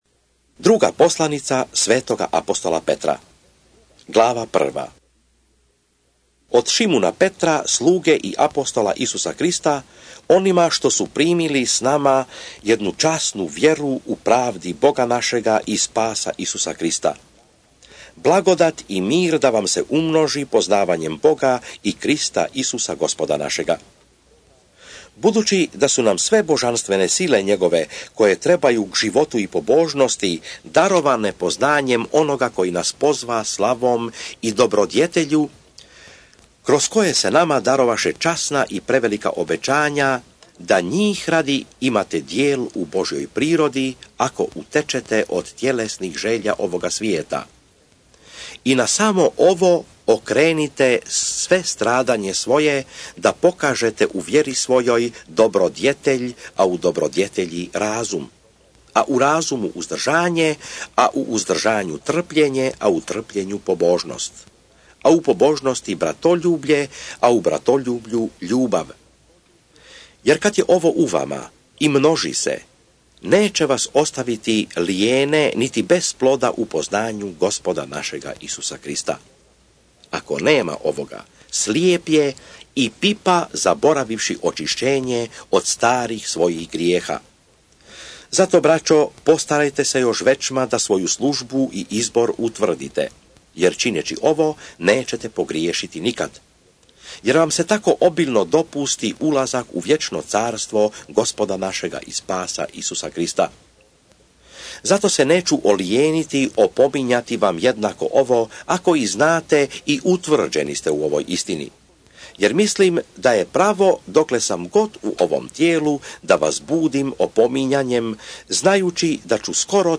2 PETAR(ČITANJE) - Bible expounded
SVETO PISMO – ČITANJE – Audio mp3 2 PETAR glava 1 glava 2 glava 3